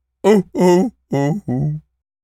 seal_walrus_death_slow_03.wav